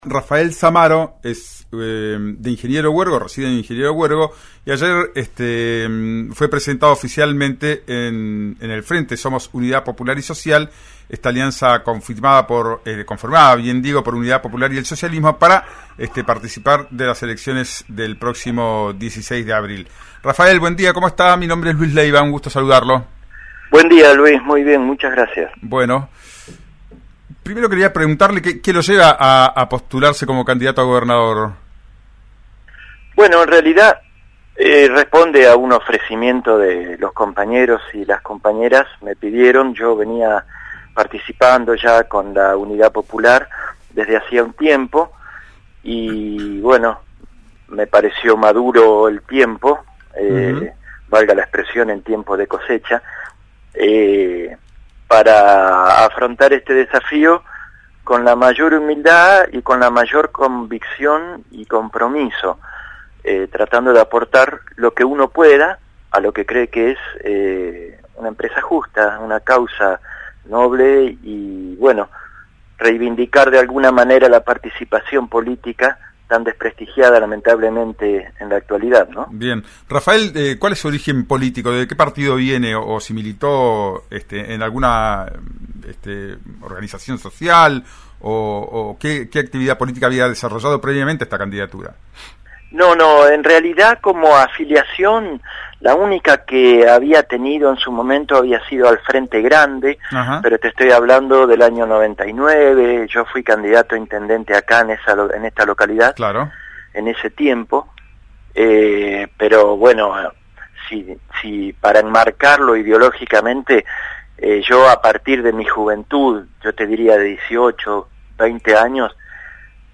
en «Ya es tiempo», por RÍO NEGRO RADIO.